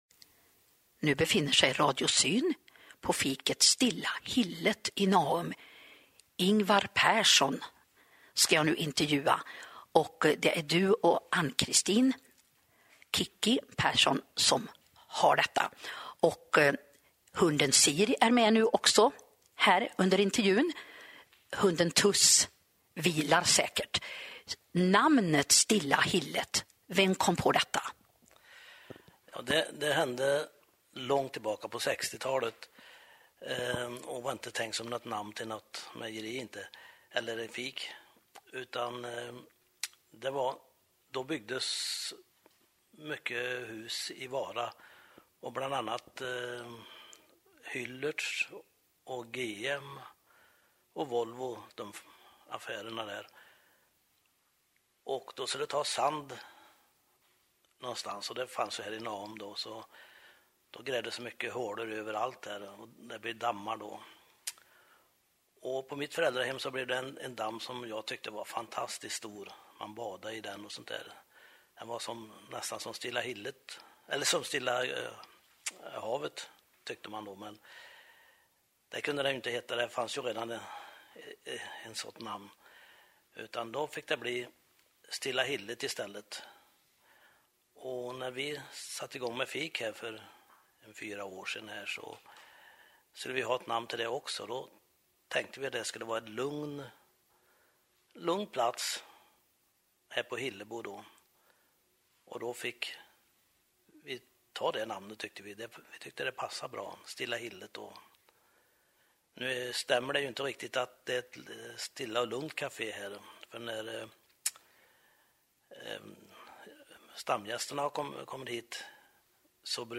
Intervjuar